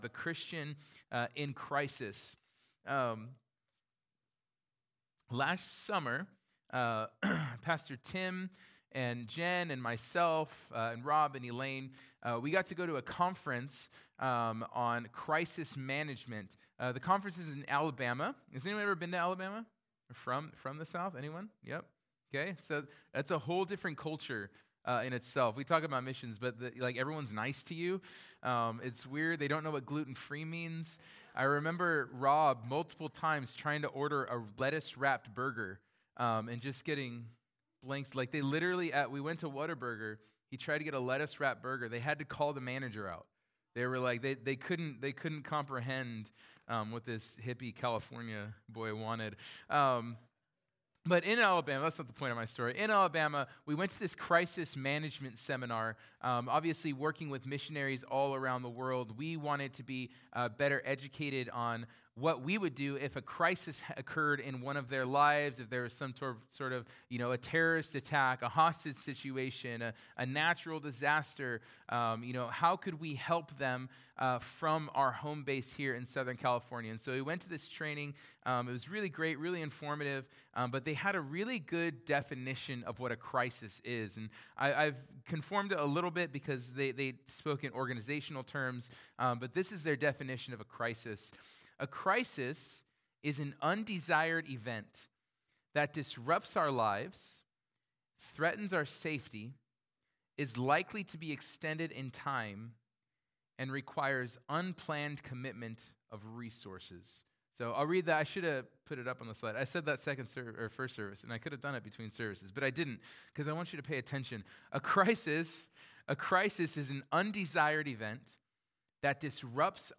The+Christian+In+Crisis+2nd+Service.mp3